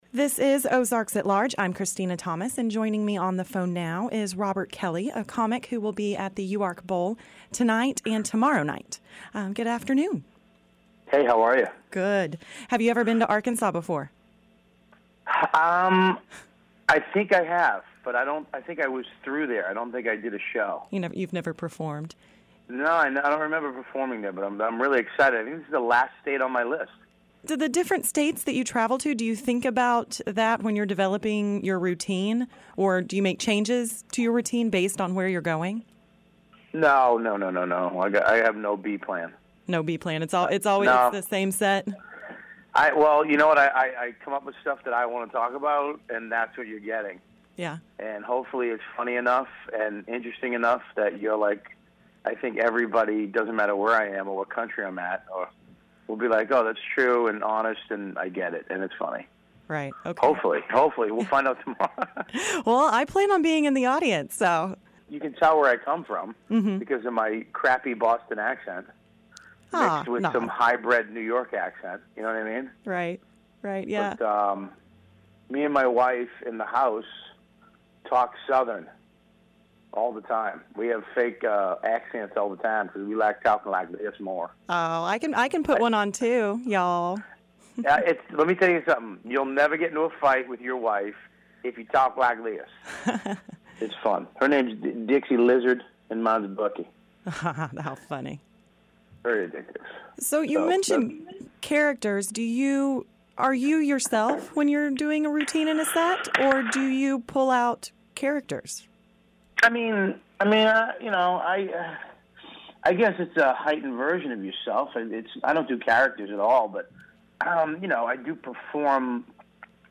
TV and movie funny guy Robert Kelly will perform three shows tonight and tomorrow night at UARK Bowl on Dickson Street. We talk with Robert prior to his Arkansas debut.